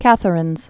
(kăthə-rĭnz, kăthrĭnz)